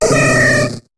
Cri de Teddiursa dans Pokémon HOME.